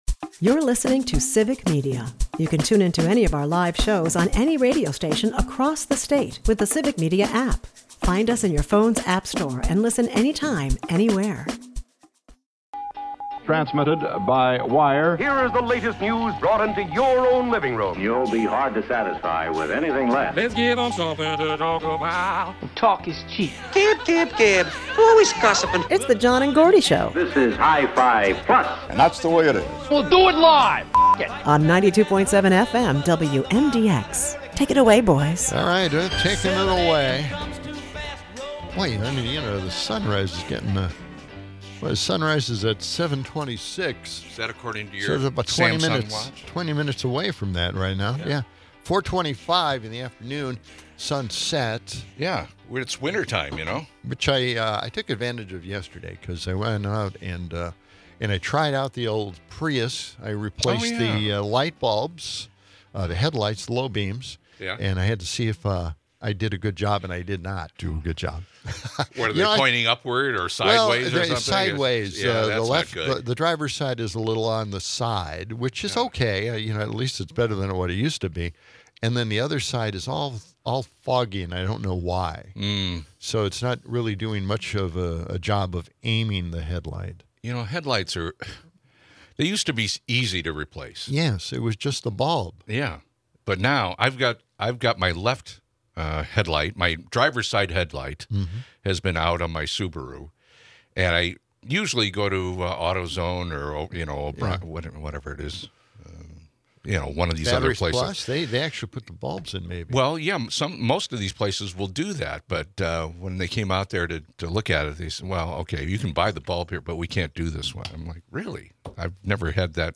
Swearing proves beneficial according to new studies, possibly boosting performance and breaking social constraints. The hosts ruminate on the bizarre naming of the Kennedy Center after Trump, stirring controversy over merits and legacy. They critique vacuous TV like 'Emily in Paris' while listeners weigh in on swearing's social etiquette.